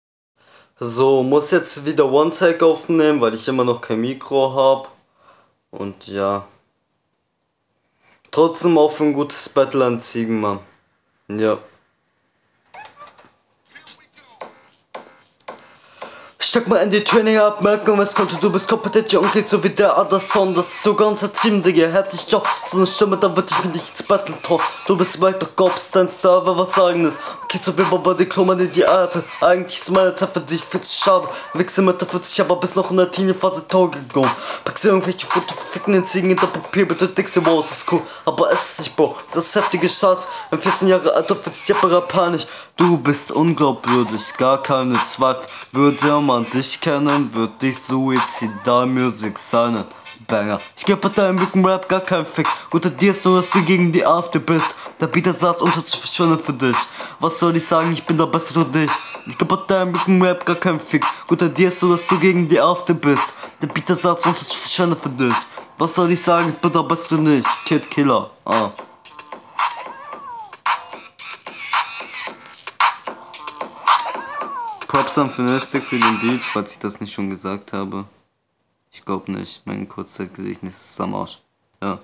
klingt bisschen ob du besoffen bist.